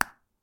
ソース類キャップ開
seasoning_lid3.mp3